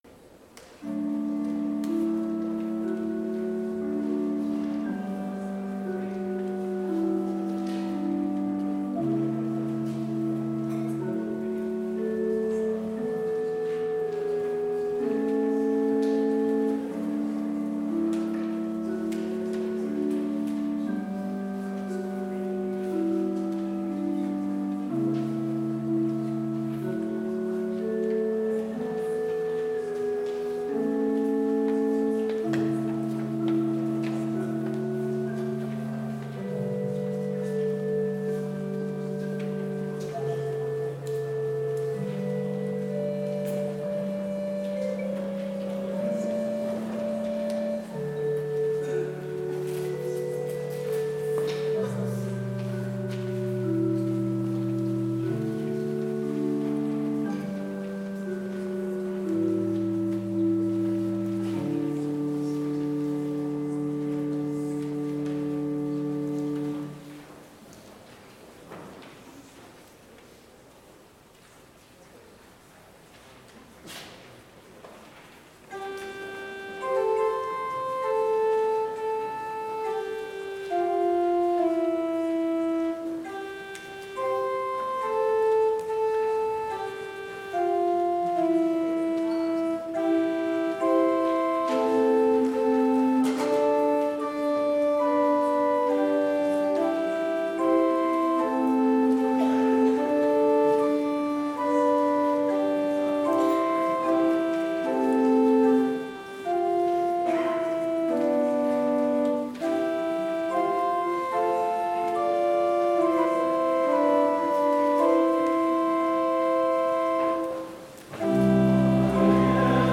Complete service audio for Chapel - January 20, 2022
Prelude Hymn 177 - I Am Jesus' Little Lamb